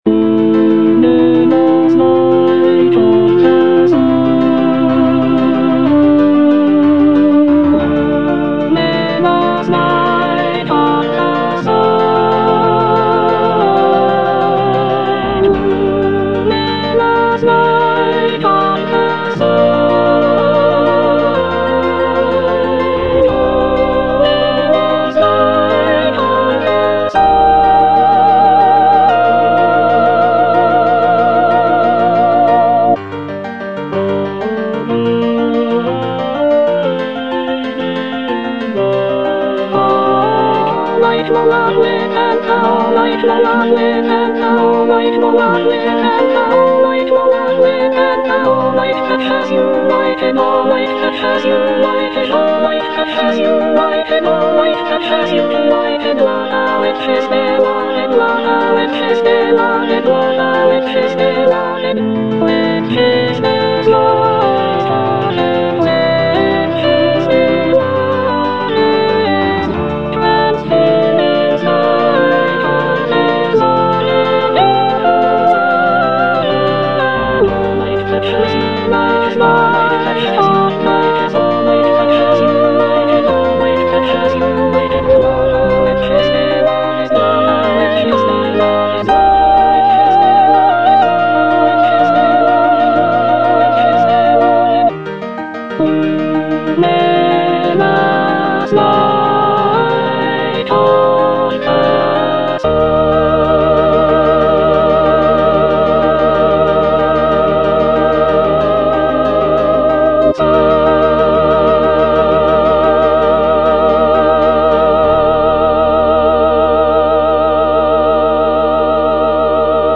(soprano I) (Emphasised voice and other voices) Ads stop